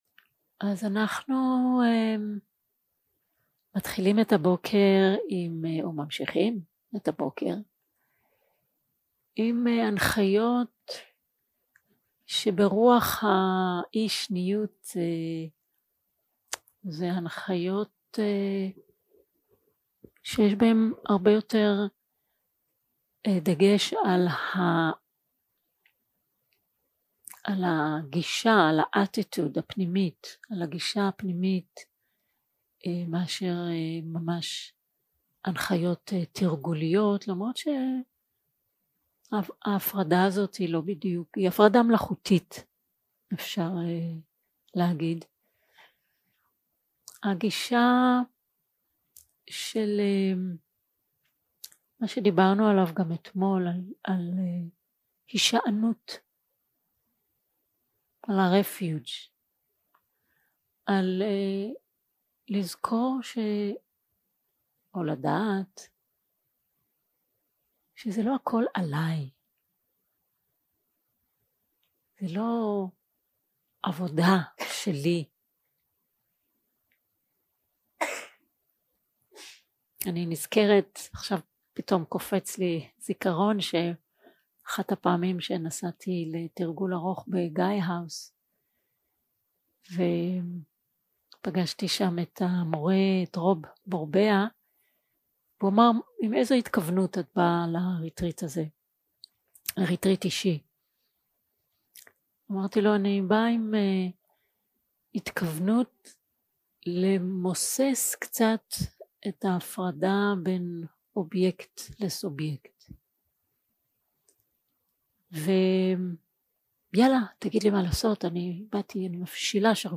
יום 2 – הקלטה 1 – בוקר – הנחיות למדיטציה – אי-שניות
Guided meditation שפת ההקלטה